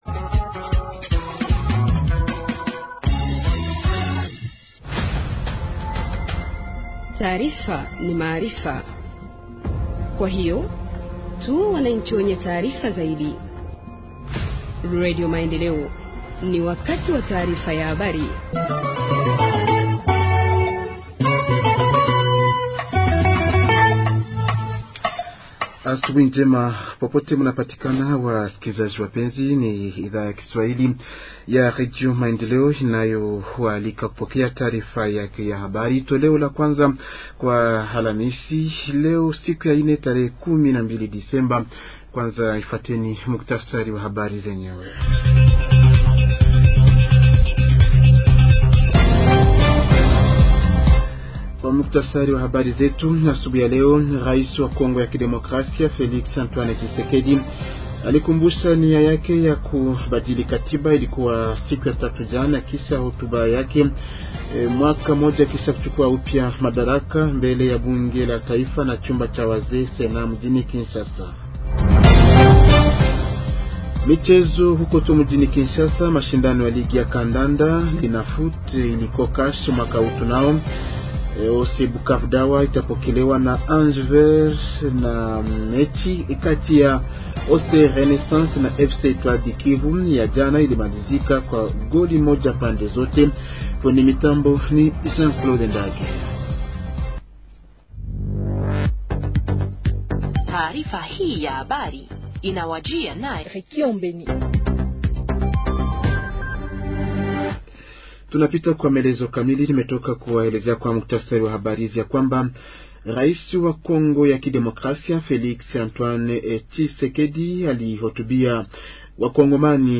Journal en swahili du 12 décembre 2024 – Radio Maendeleo